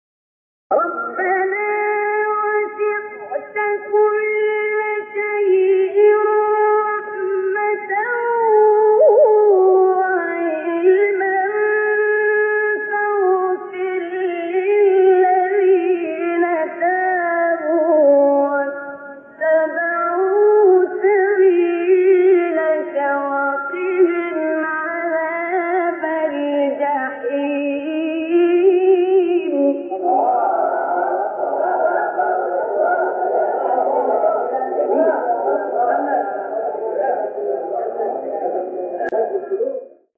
سوره : غافر آیه: 7 استاد : عبدالباسط محمد عبدالصمد مقام : رست قبلی بعدی